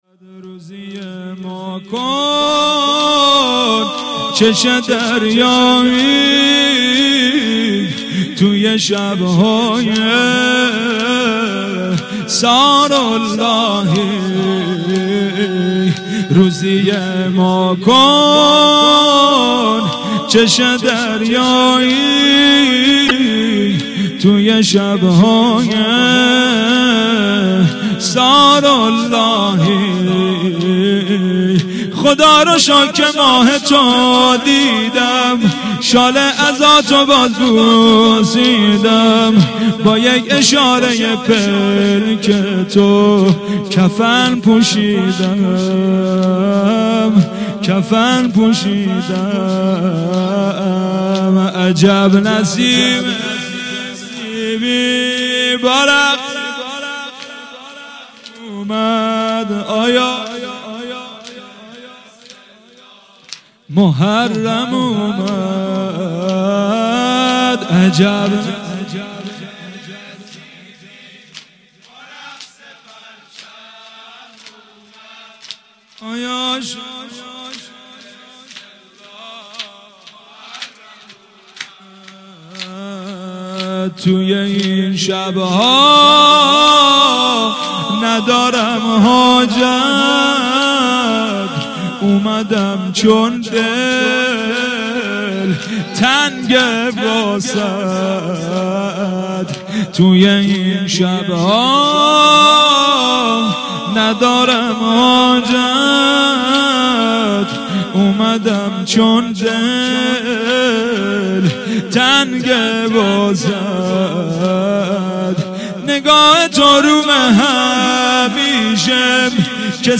مداحی
برای شب اول محرم